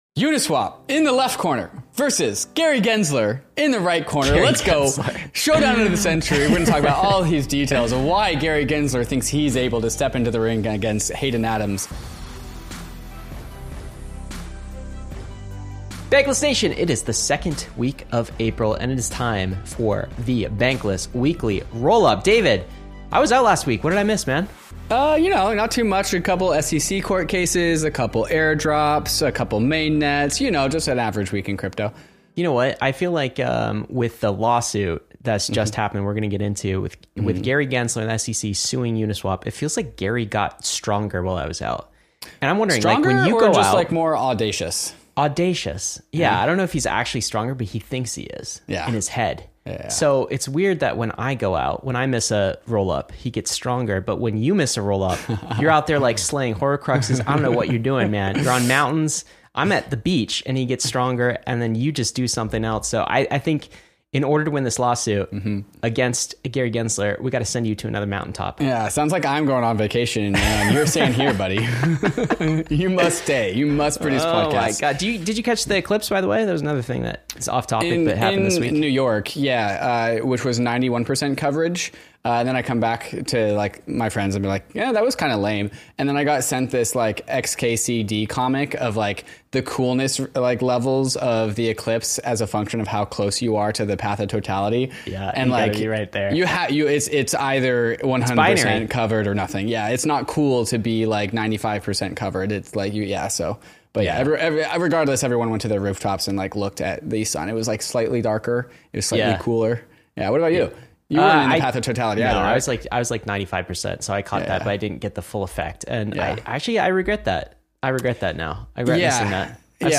public ios_share Bankless chevron_right ROLLUP: Uniswap vs. SEC | Eigenlayer Mainnet | Blackrock BUIDL Fund | $TNSR Solana Airdrop whatshot 4 snips Apr 12, 2024 The hosts serve up a humorous take on the Uniswap vs. SEC legal saga, balancing serious discussions with playful banter. They highlight the launch of Eigenlayer and BlackRock’s crypto ambitions, featuring insights on token drops and developer opportunities. The conversation shifts to market dynamics, exploring Bitcoin and Ethereum's fluctuations amid a bearish outlook for April.